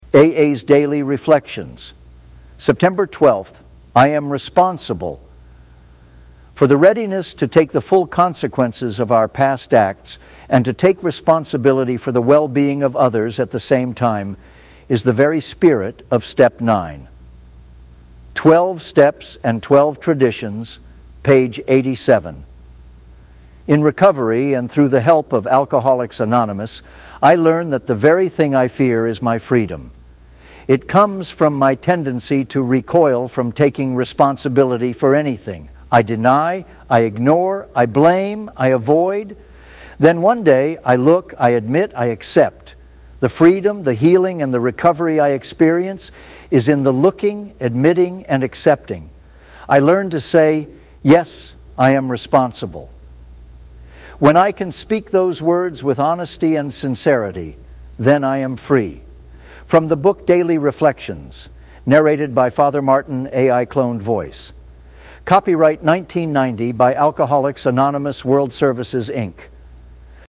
A.I. Cloned Voice.